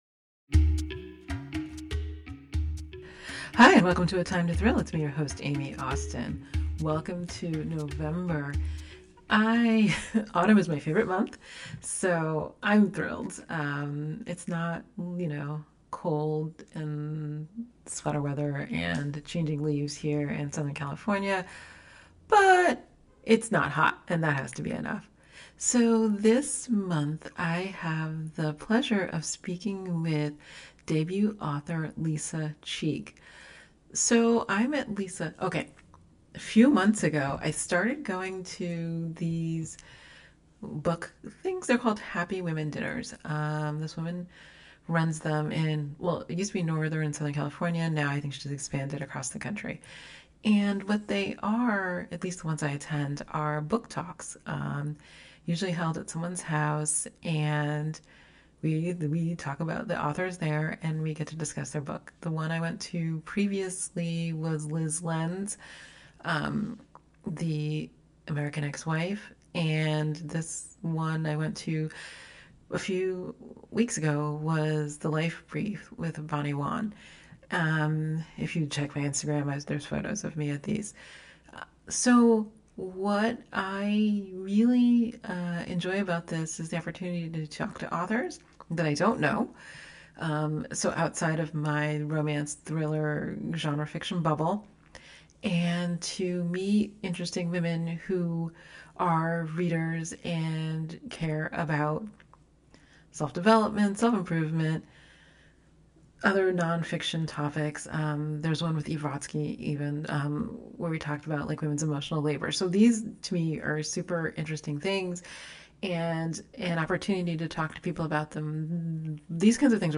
A Time to Thrill - Conversations